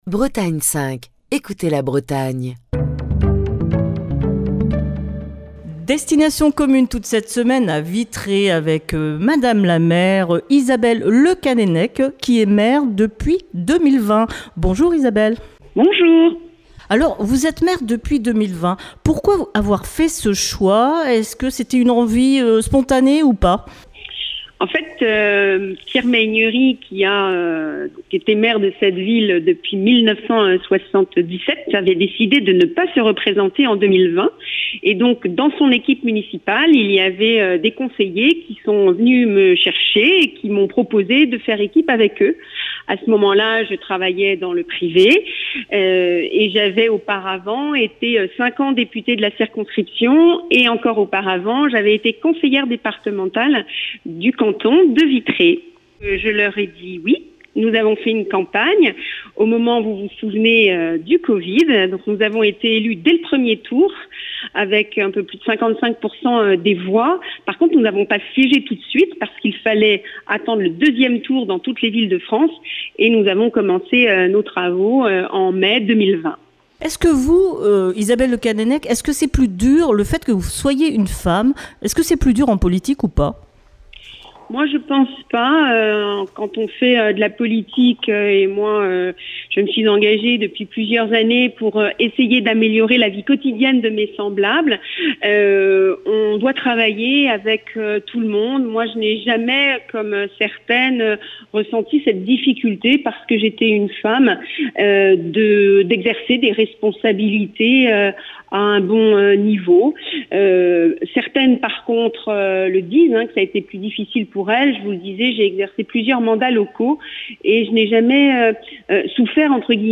au téléphone avec la maire de Vitré, Isabelle Le Callennec